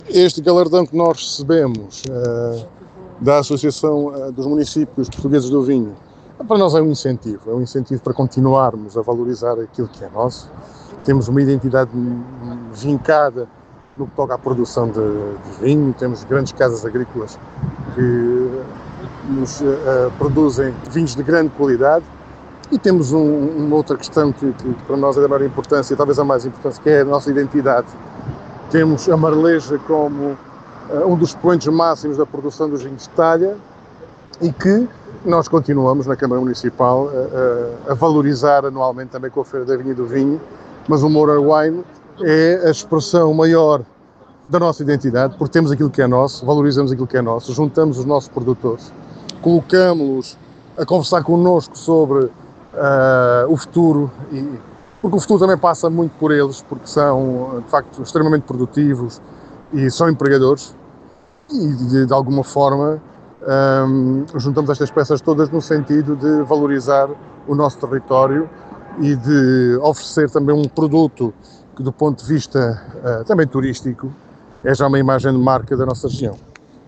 Declaracoes-Presidente-MouraWine.mp3